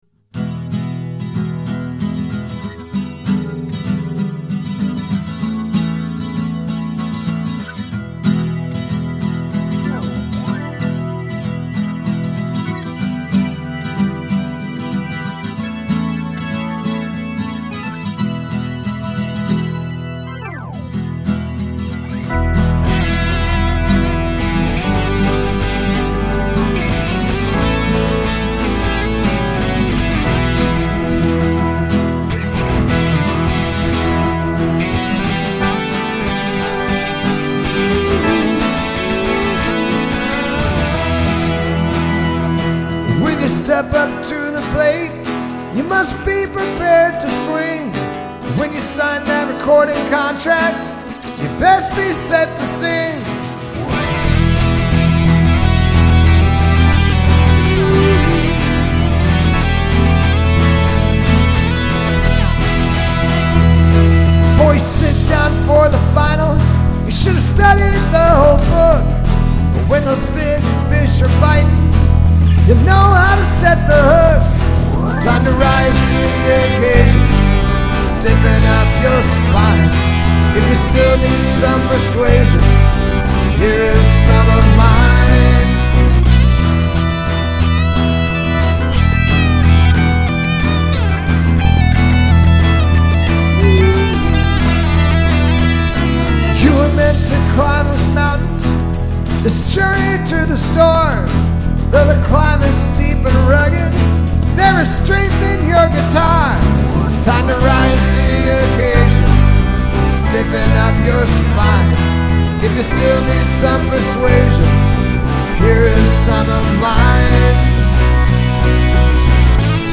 (180 b/m) - 9/6/16